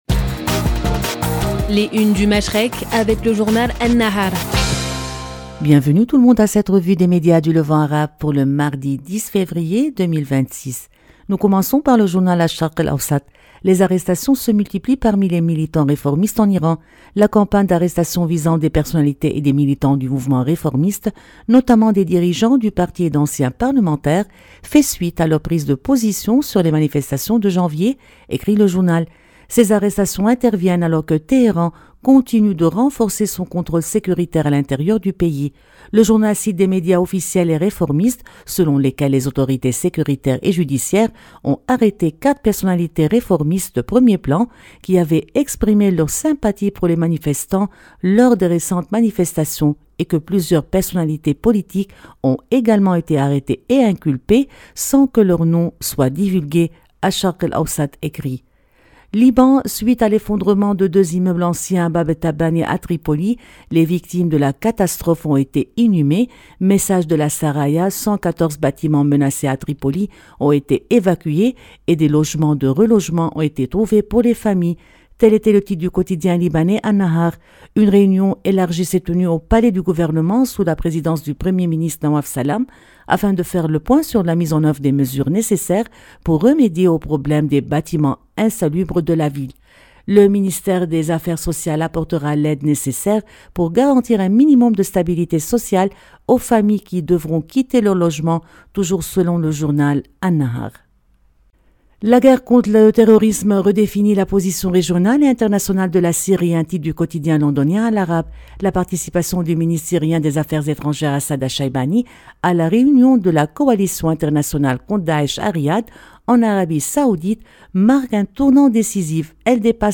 Revue de presse des médias du Moyen-Orient